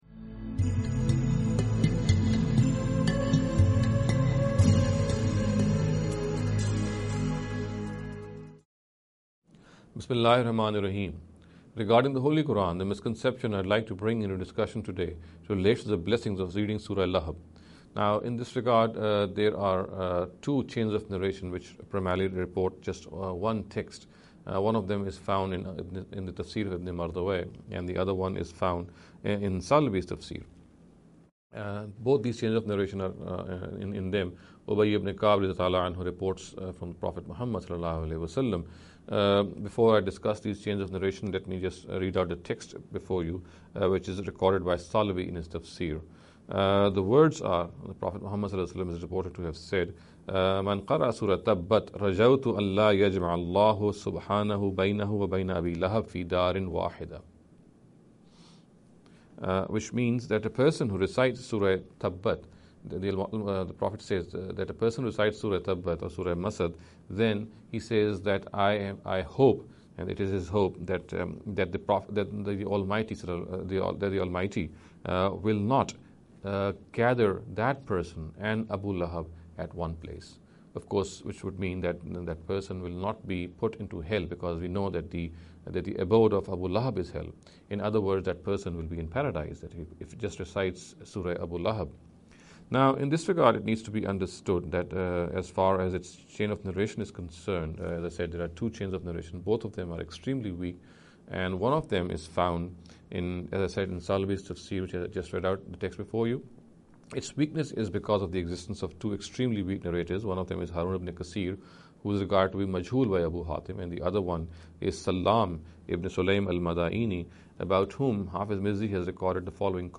In every lecture he will be dealing with a question in a short and very concise manner.